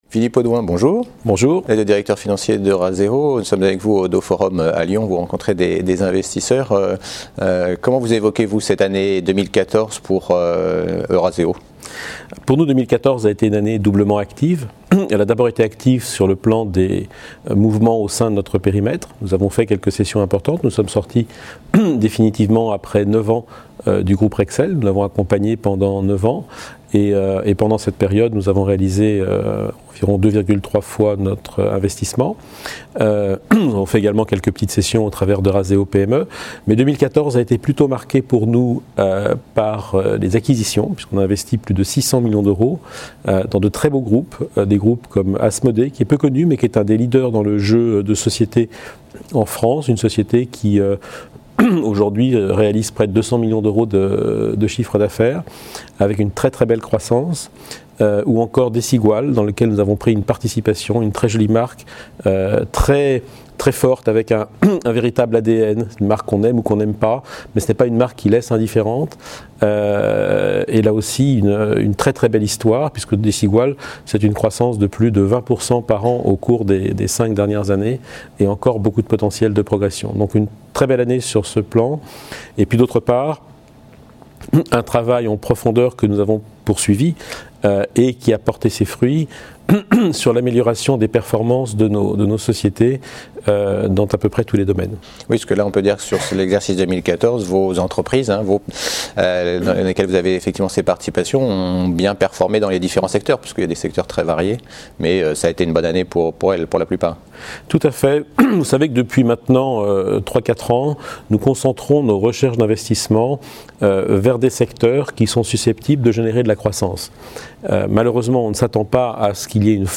Oddo Forum 2015: Année 2014 pour la société de capital investissement et perspectives